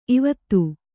♪ ivattu